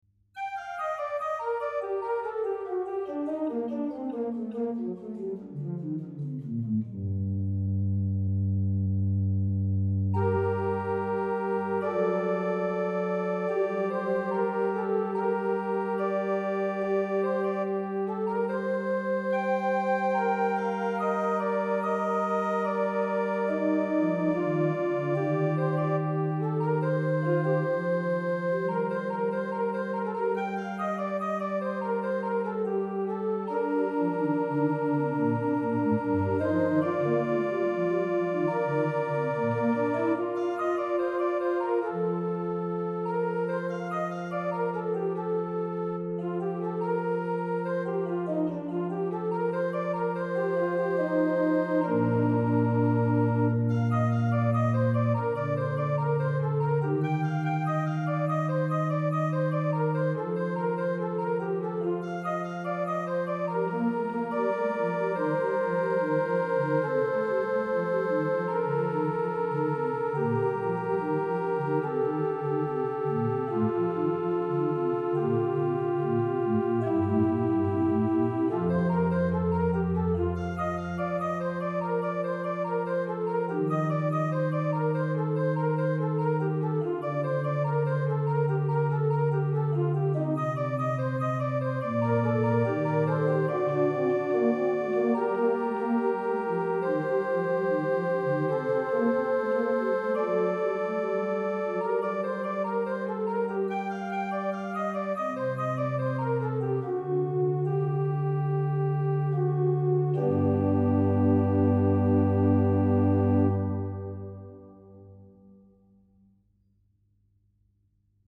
No additional effect processing have been added to the recording. The tail of release is the original of the samples. All traks were recorded with the original temperament of the organ with the exeption of tracks by J.S.Bach that were recorded with equal temperament..
Principale + Voce Umana